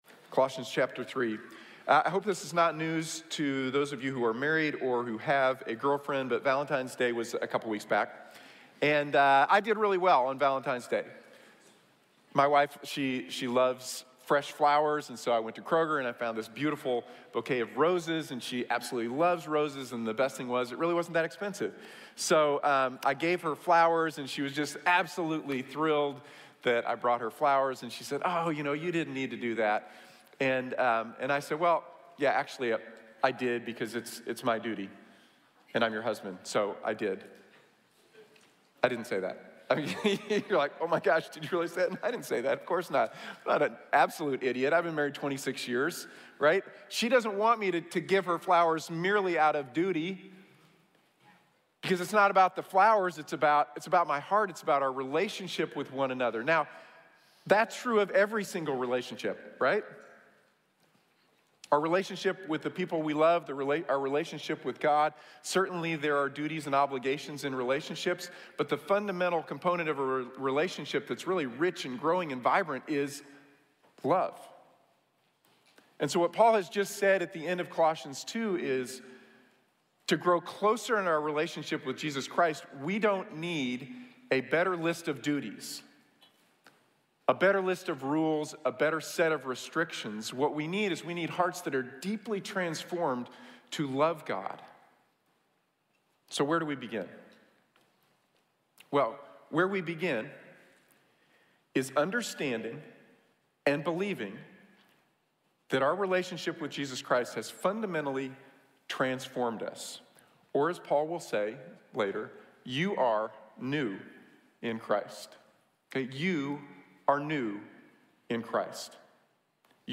Renewed | Sermon | Grace Bible Church